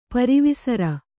♪ pari visara